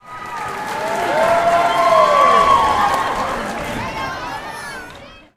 cheer1.ogg